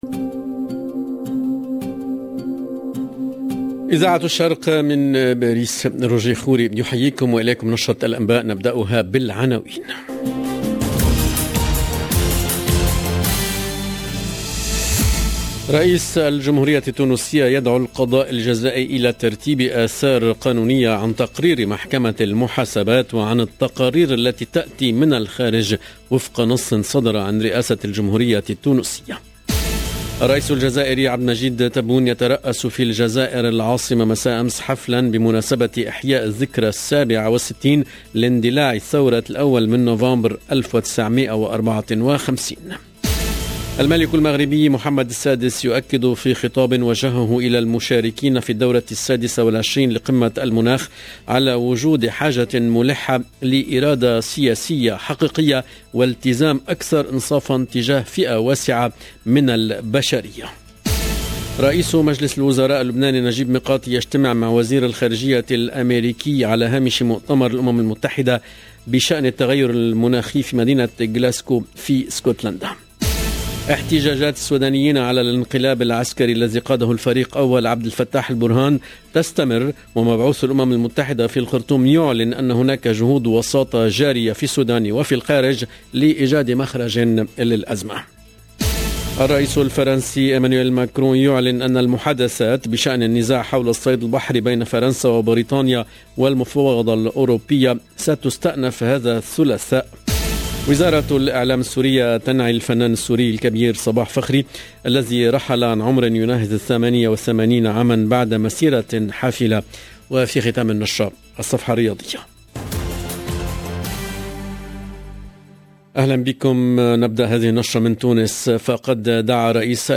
LE JOURNAL DE 12H30 EN LANGUE ARABE DU 2/11/2021
EDITION DU JOURNAL EN LANGUE ARABE DU 2/11/2021